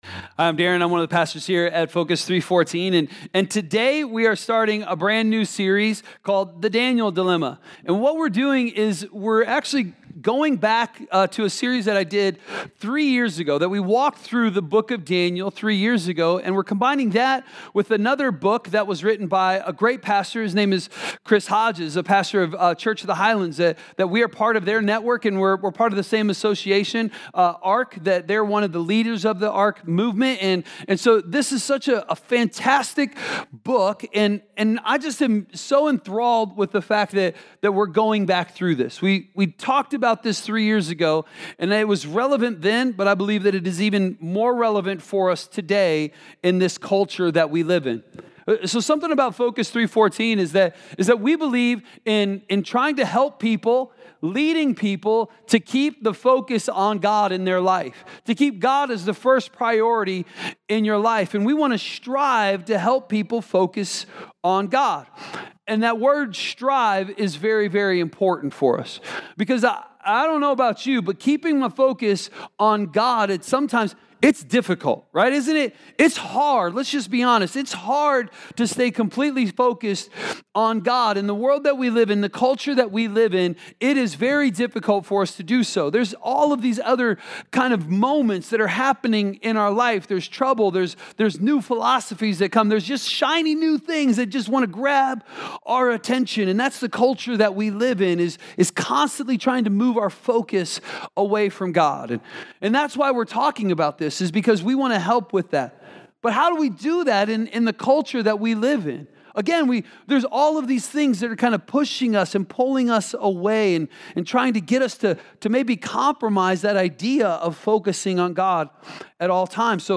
A message from the series "The Daniel Dilemma."